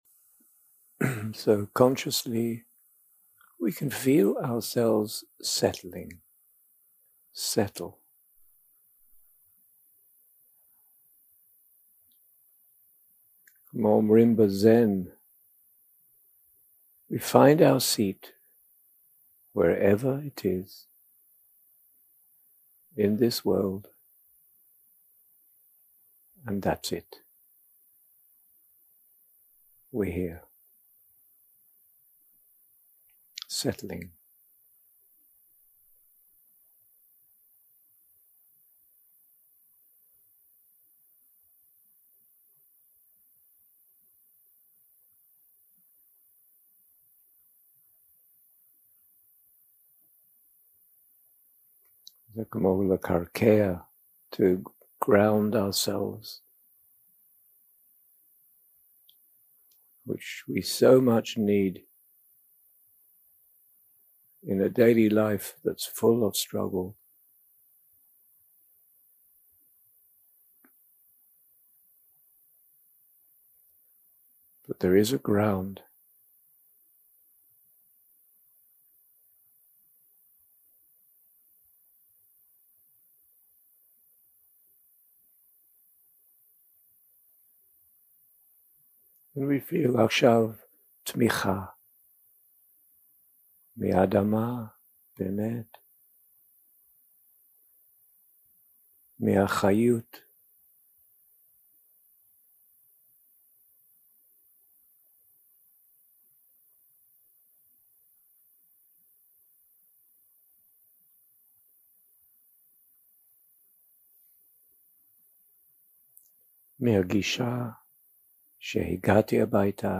יום 3 – הקלטה 6 – צהריים – מדיטציה מונחית
Dharma type: Guided meditation